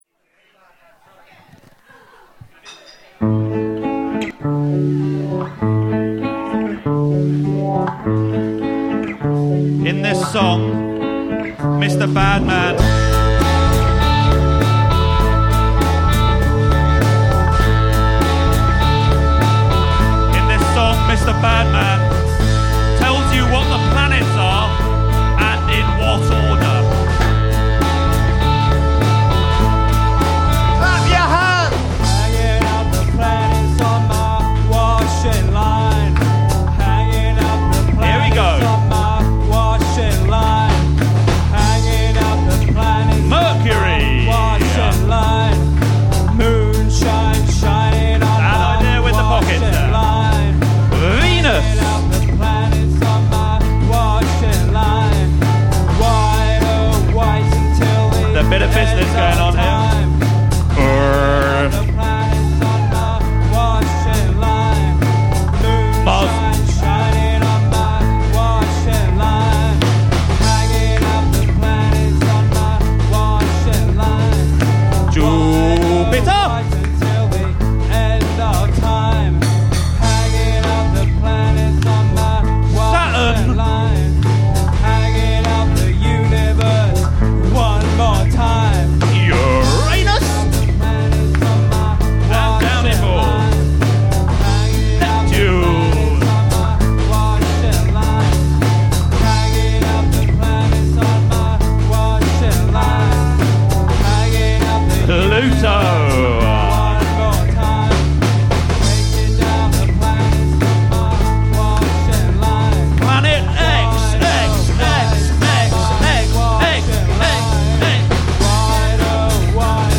the_planets_live.mp3